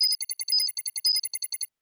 sfx_skill 03_2.wav